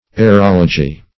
Aerology \A`["e]r*ol"o*gy\, n. [A["e]ro- + -logy: cf. F.